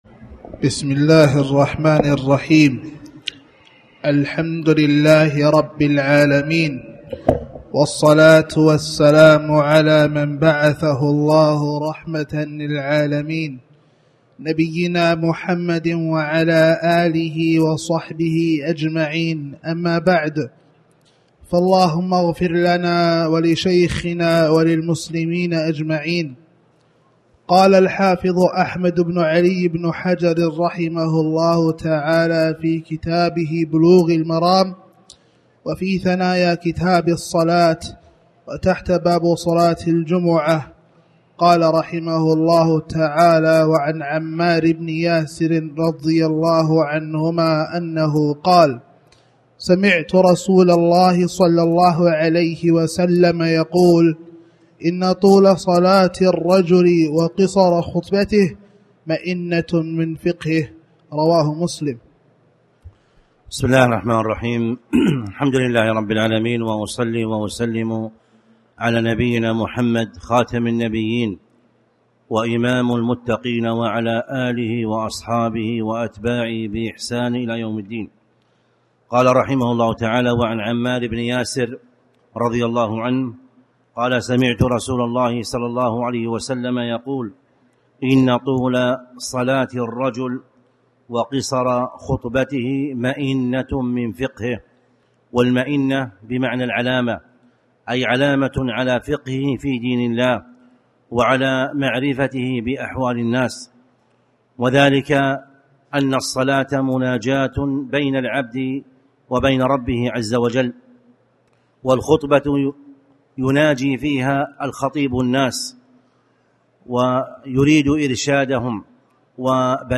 تاريخ النشر ٢٥ ربيع الأول ١٤٣٩ هـ المكان: المسجد الحرام الشيخ